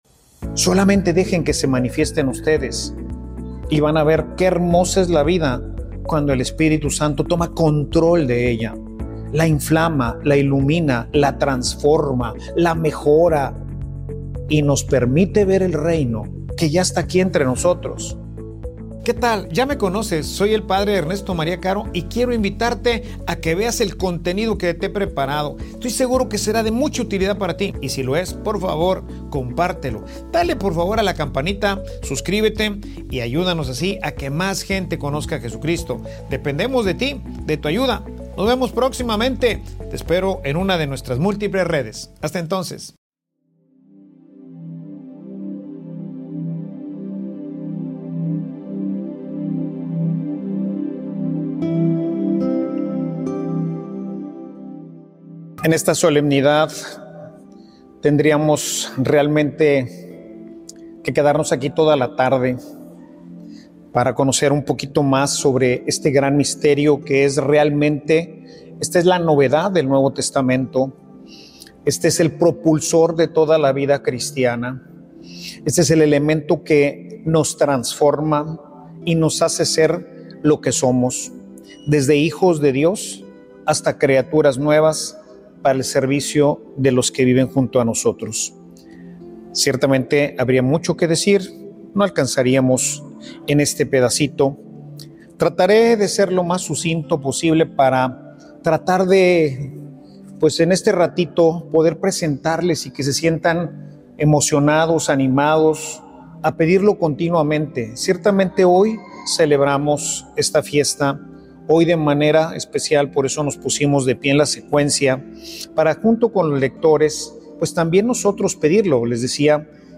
Homilia_Un_poder_sin_limites_vive_dentro_de_ti.mp3